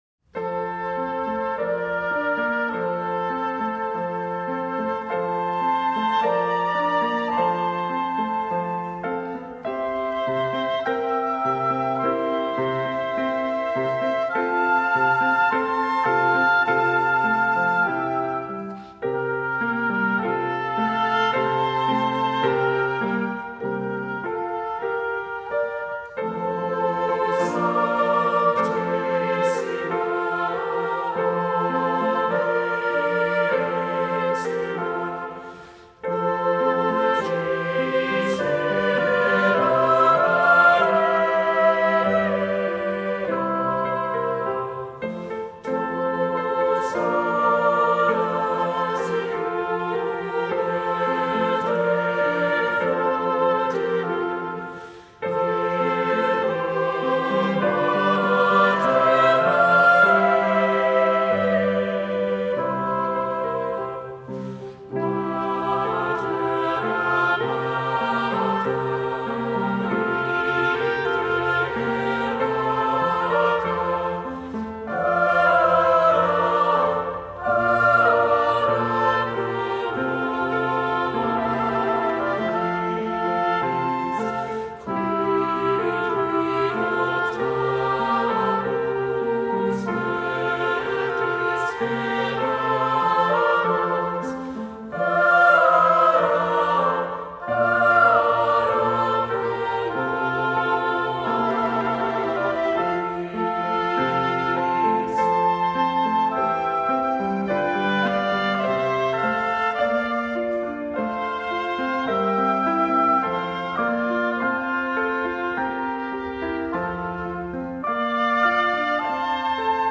Voicing: SAB; Assembly